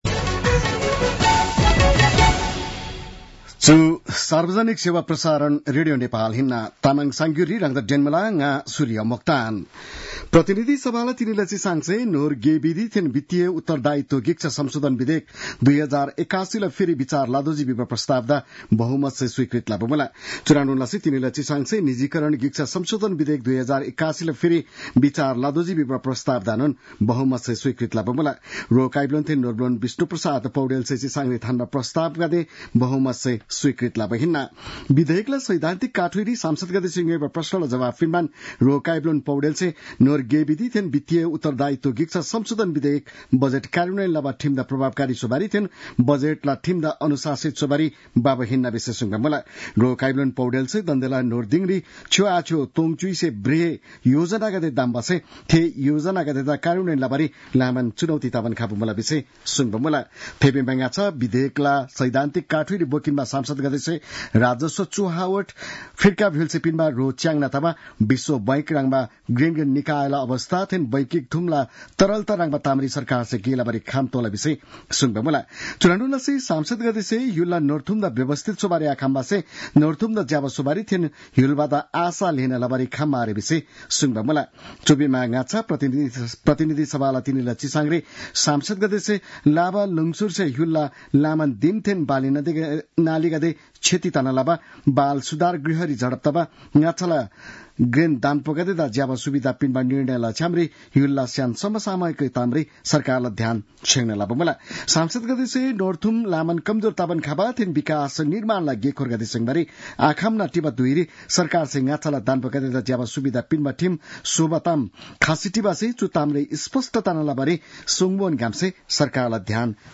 तामाङ भाषाको समाचार : ३ चैत , २०८१